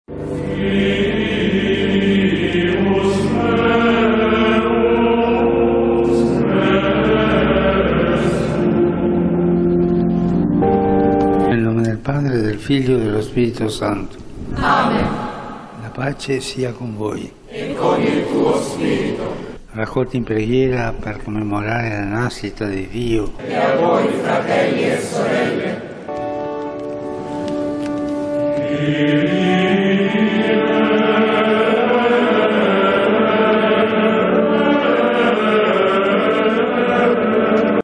වතිකාන ශාන්ත බැසිලිකා දෙව්මැදුරේදී ශුද්ධ වූ ෆැන්සිස් පාප් වහන්සේගේ ප්‍රධානත්වයෙන් පැවති නත්තල් දේව මෙහෙය රෝමයේ වේලාවෙන් රාත්‍රි 7.30 ට ආරම්භ කෙරුණා .